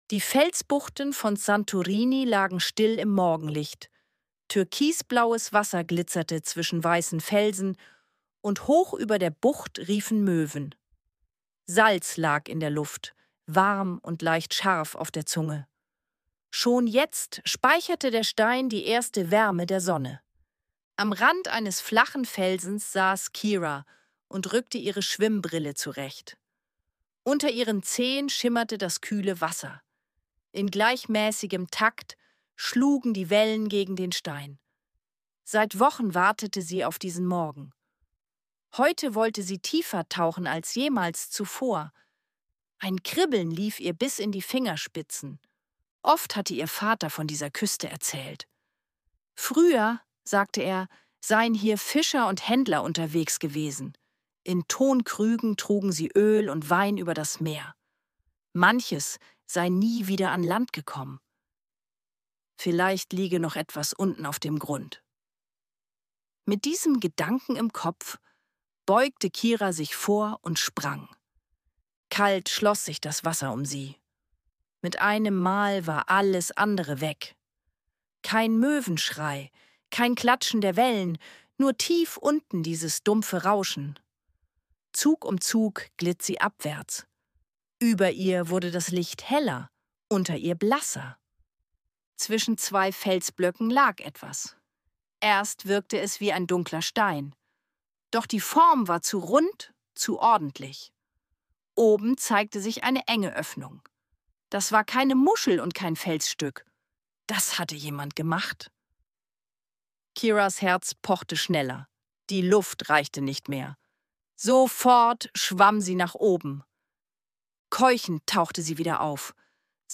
Eine ruhige Abenteuergeschichte für Kinder über ein verborgenes Fundstück im Meer von Santorini.